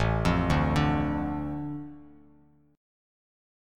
Asus4#5 chord